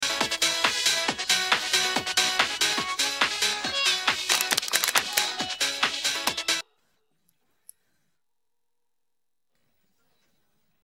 배경 음악